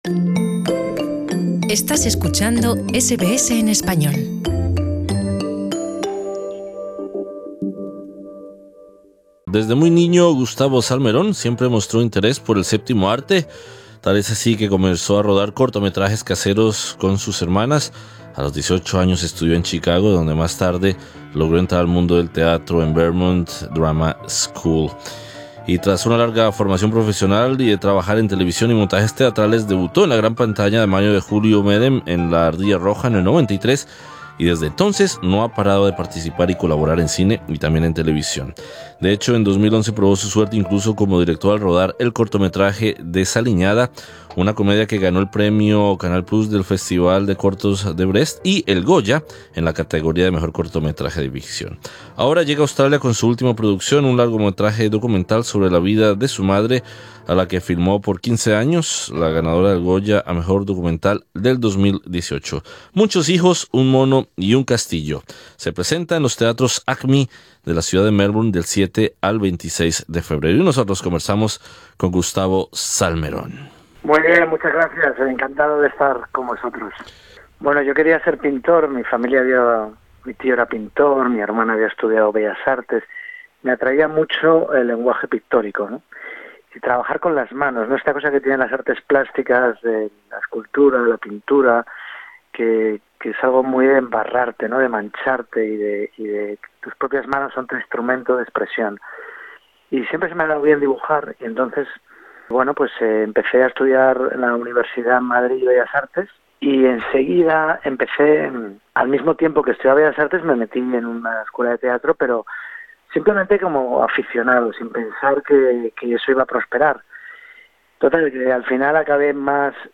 Conversamos con Gustavo Salmeron.